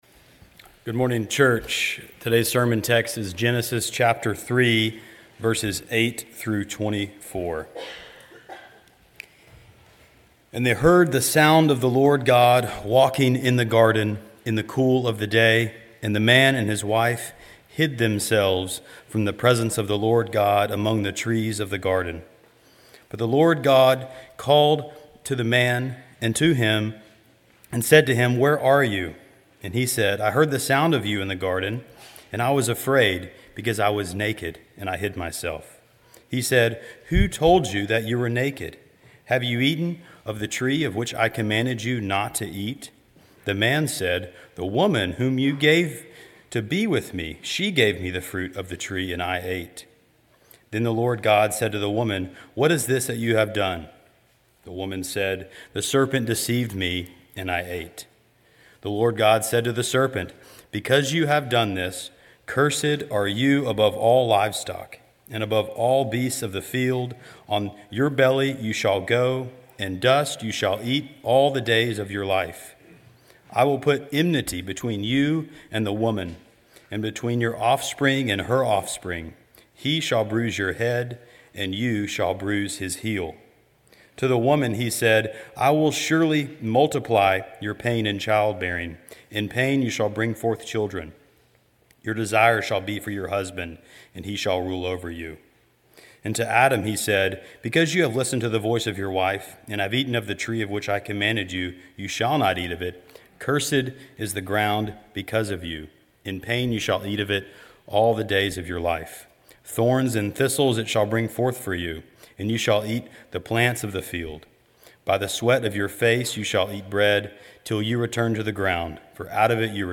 sermon2.15.26.mp3